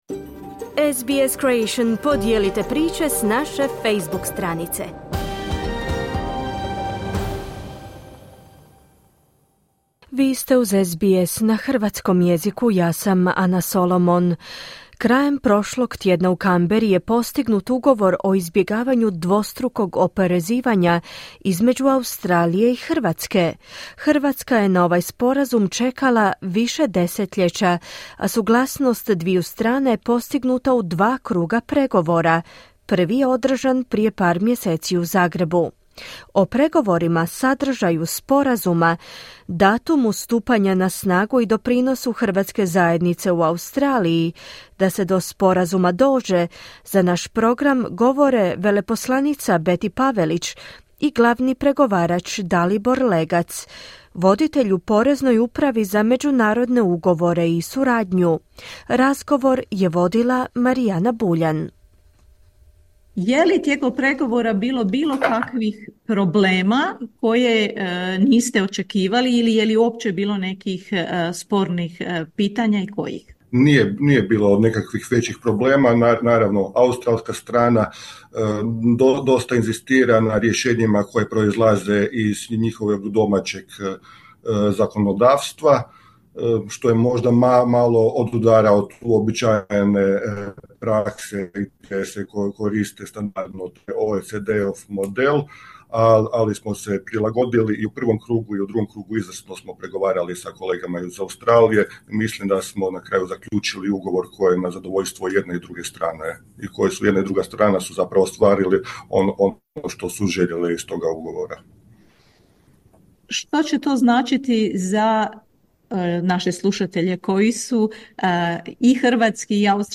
Dvostruko oporezivanje, intervju Share